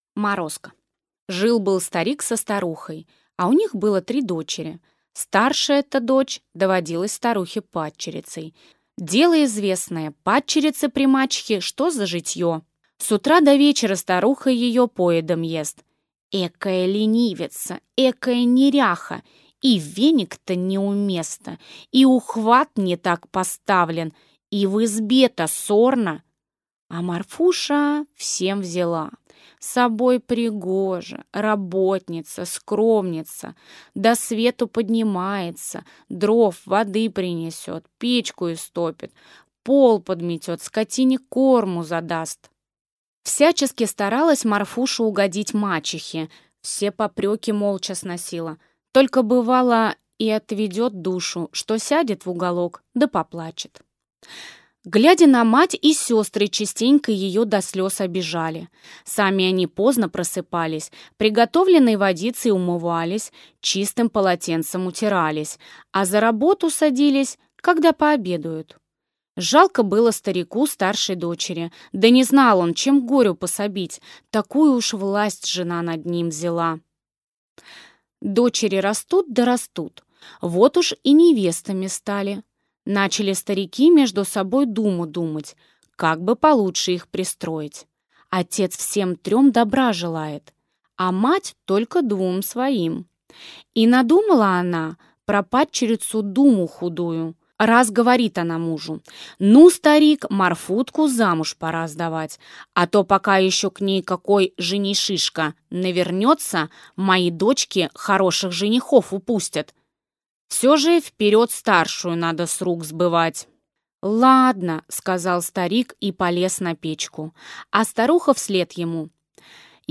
Аудиокнига Морозко | Библиотека аудиокниг